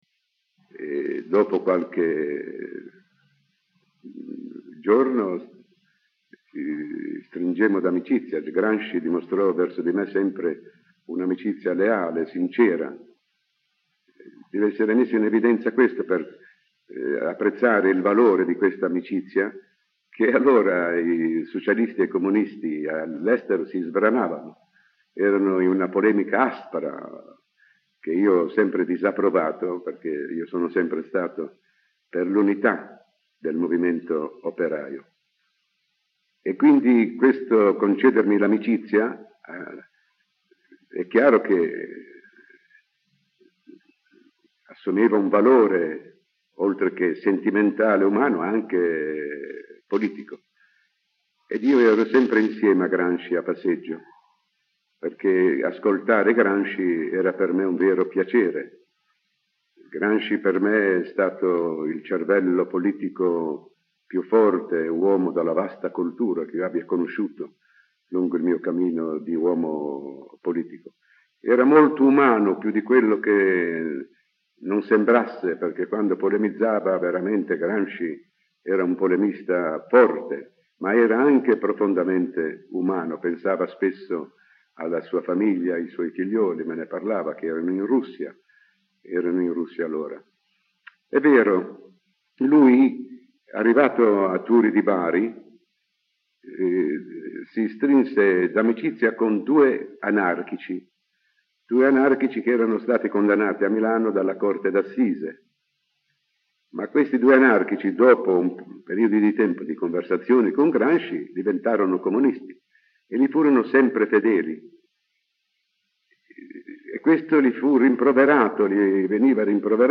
Listen to Sandro Pertini’s testimony kept at the Museum (in Italian).